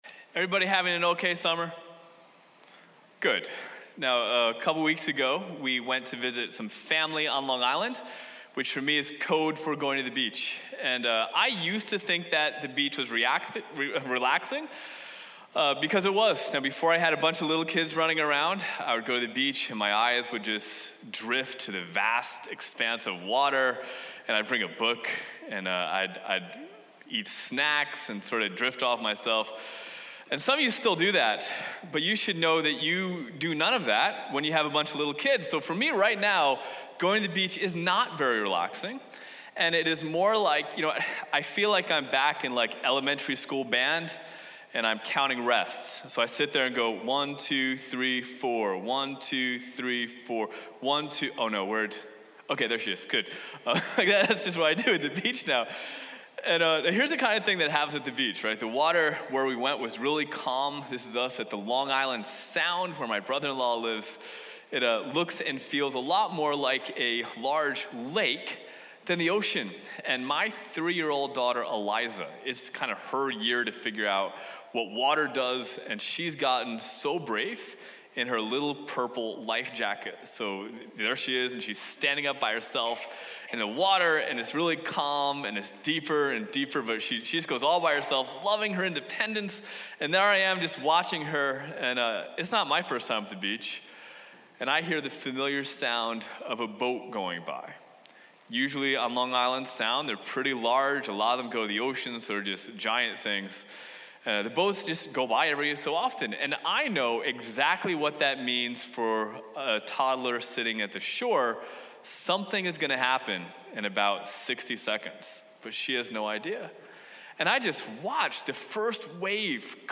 Sermon-Aug16.mp3